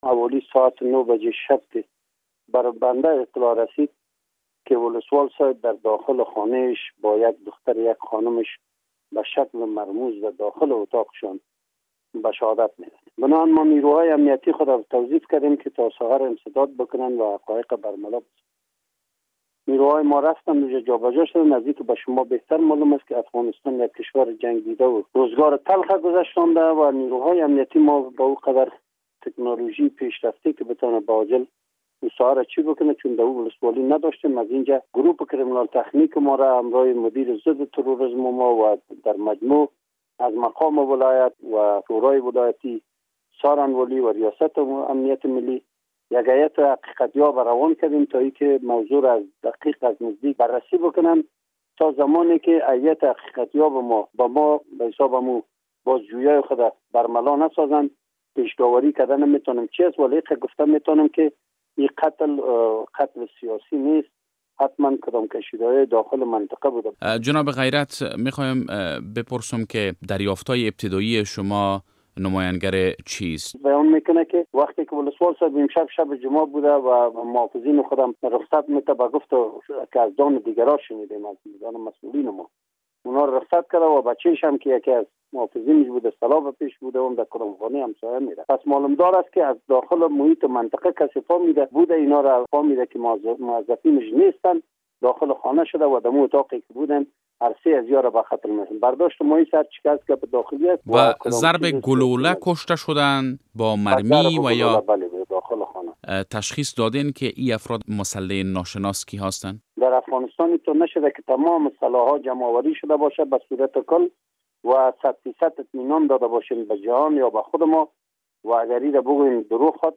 مصاحبه با عبدالعزیز غیرت قوماندان امنیهء جوزجان در مورد قتل ولسوال قرقین